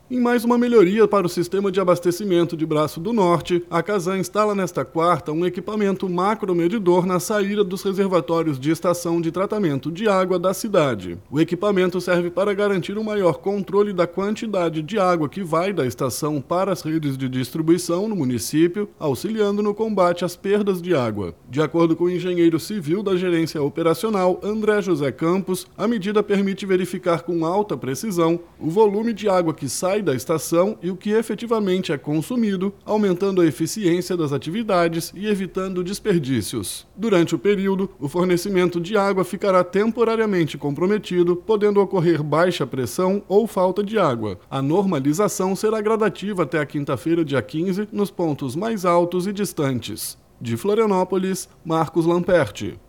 BOLETIM – Casan instala equipamento macromedidor para combater perdas em Braço do Norte